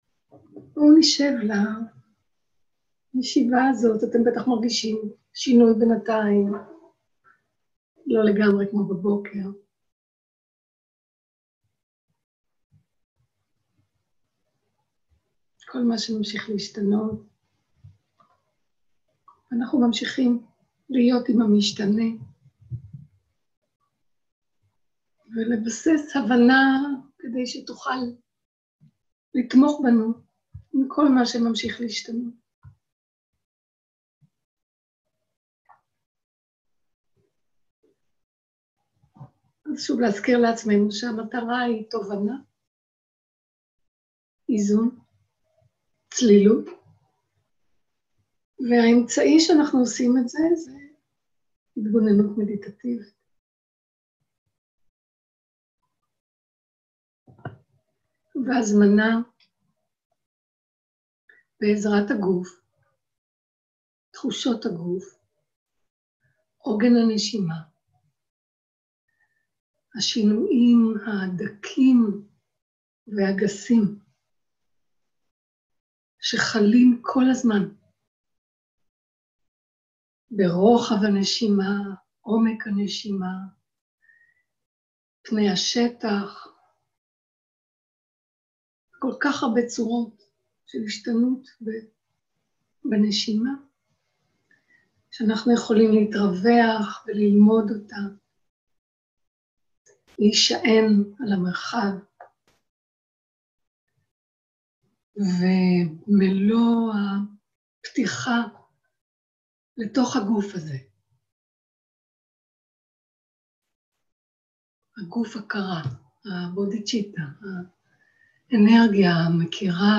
מדיטציה מונחית
סוג ההקלטה: מדיטציה מונחית
עברית איכות ההקלטה: איכות גבוהה מידע נוסף אודות ההקלטה